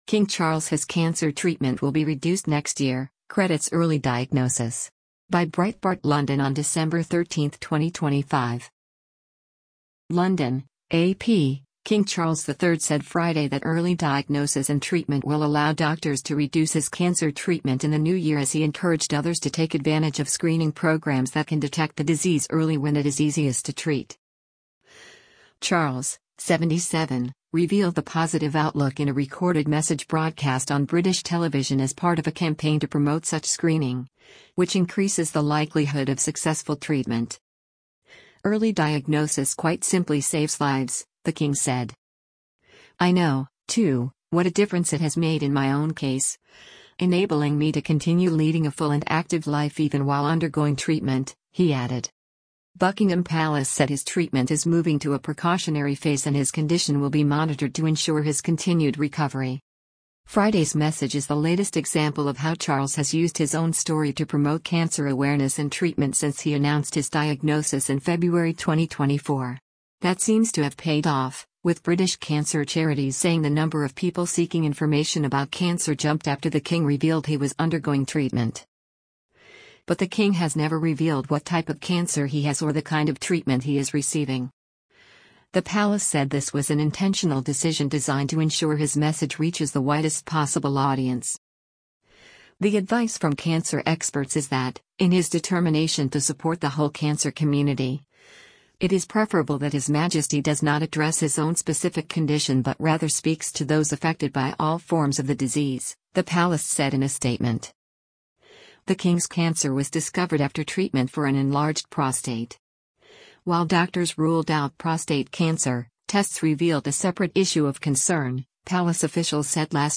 Charles, 77, revealed the positive outlook in a recorded message broadcast on British television as part of a campaign to promote such screening, which increases the likelihood of successful treatment.